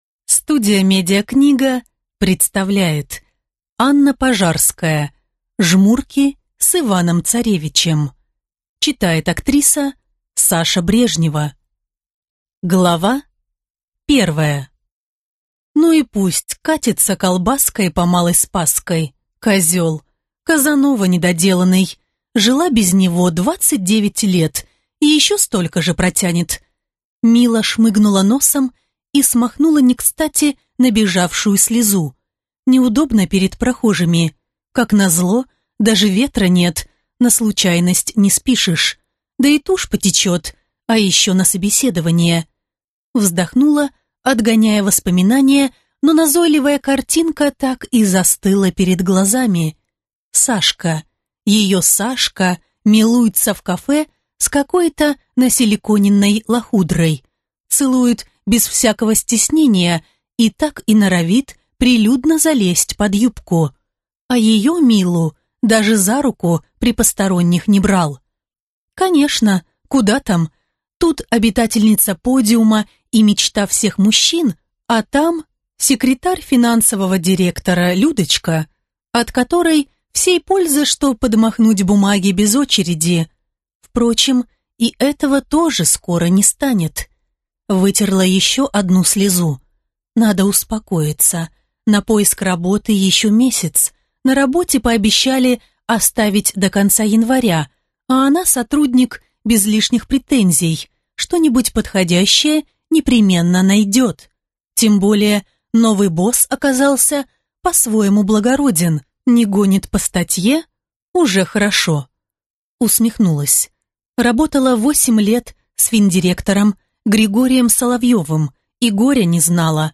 Аудиокнига Жмурки с Иваном-царевичем | Библиотека аудиокниг